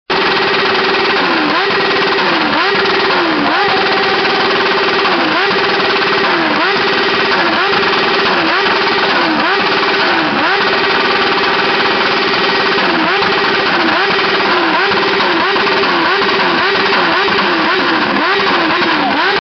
Bien plus tard, la nuit fut plus encore, chaotique, car même si la décence nous aura épargné les wheelings et autres burns, les ruptures restent au programme du « gratin » des motards !
Et voilà ce que ça donne entre 19 heures et 5 heures du matin (pour toi seulement 20 petites secondes)
Le cocktail désinhibiteur effet de masse et/ou alcool suffit au quidam qui possède une 150 cv à 15 000 t/mn greffée à des pots d’échappement très spéciaux.
concoursbruit.mp3